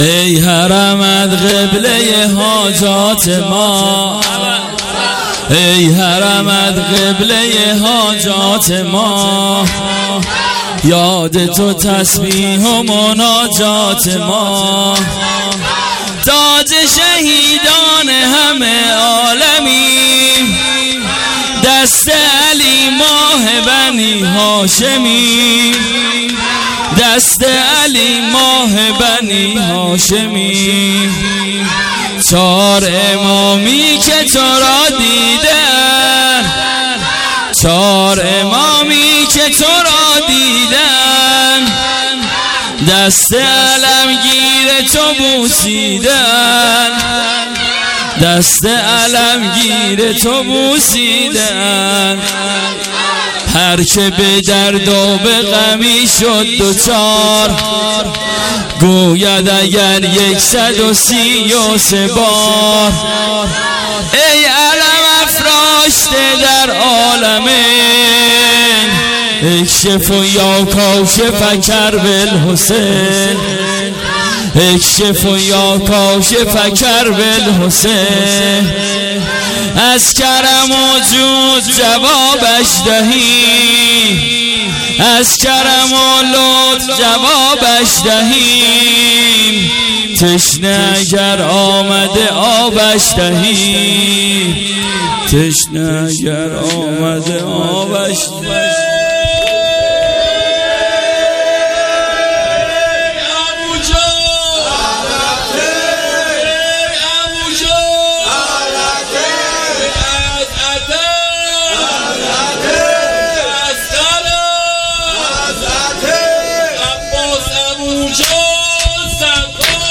محرم الحرام ۱۴۰۰